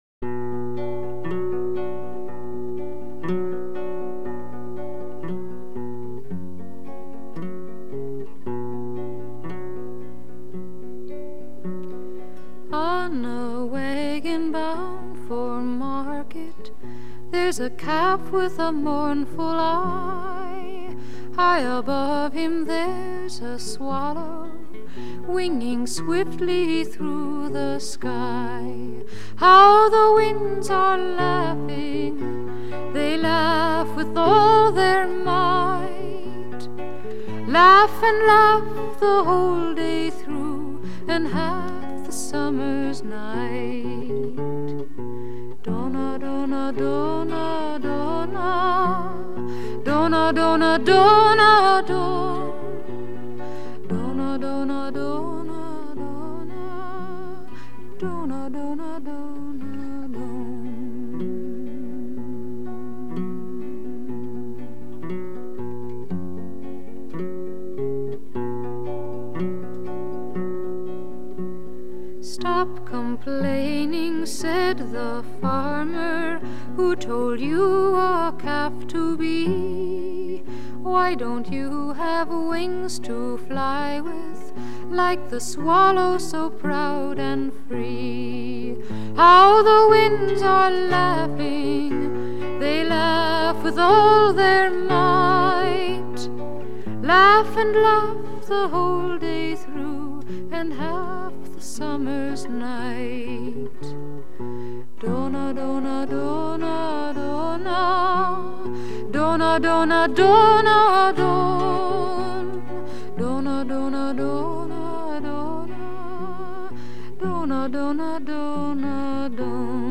因为不是原抓的录音，所以质量参差不齐，敬请原谅，同时欢迎补充相关材料。